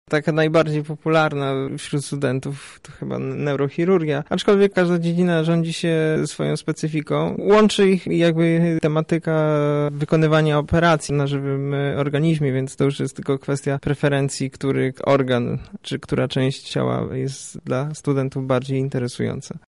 Lekarze rezydenci powiedzieli o najczęściej wybieranej dalszej ścieżce zawodowej wśród przyszłych chirurgów.